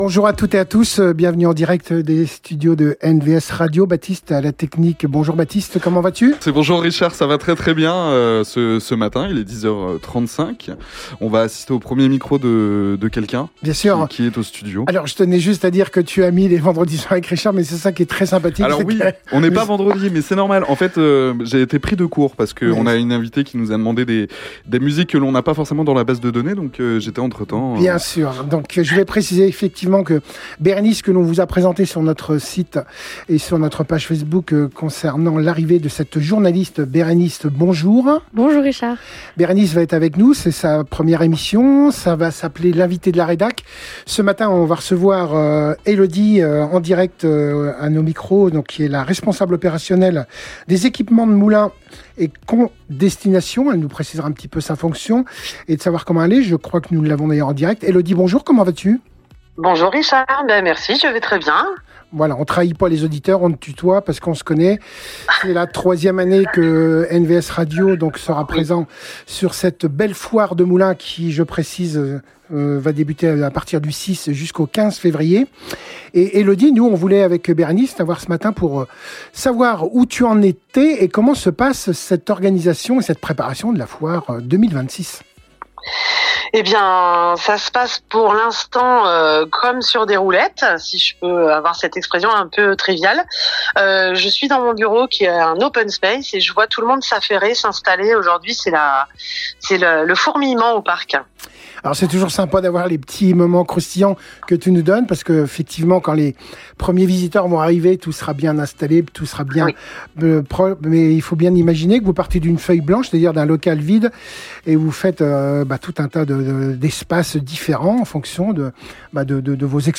recevaient en direct
interview exclusive consacrée à la Foire de Moulins 2026